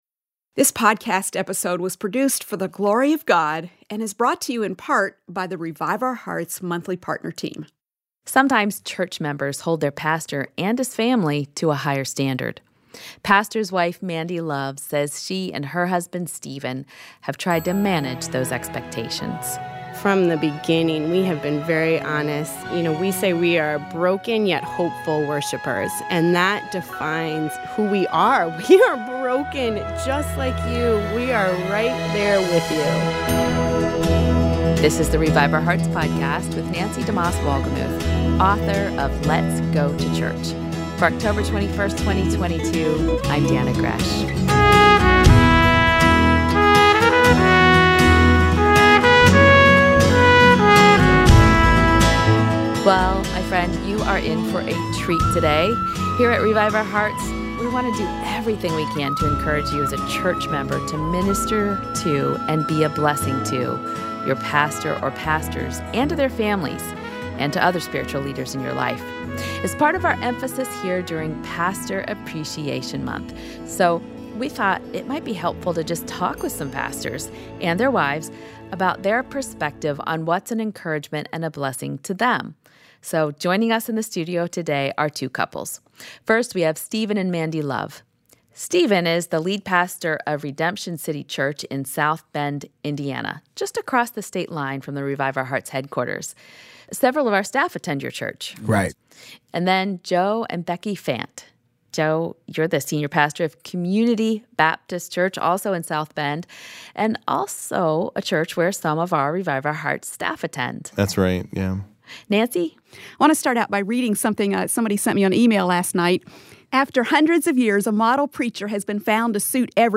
Pastors and their wives will share their struggles and how we can encourage them.